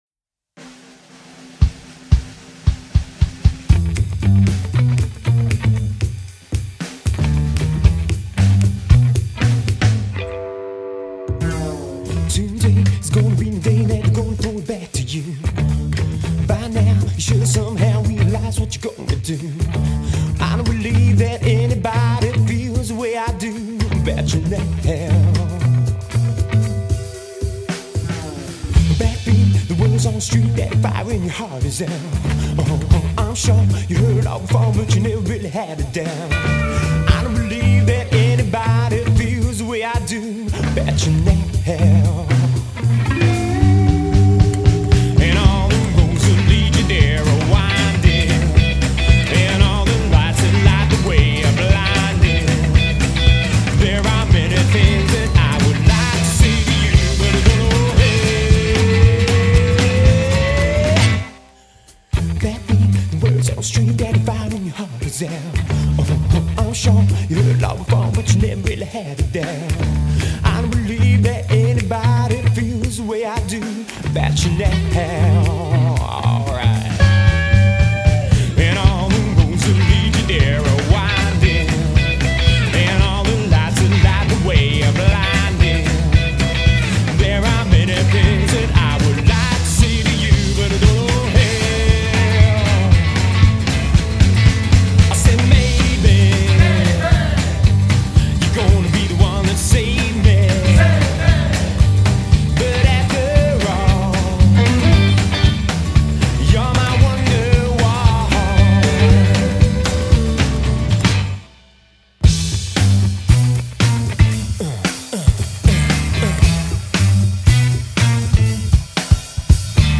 pop'n'roll á la boss hoss
That’s what we call: POP’N’ROLL !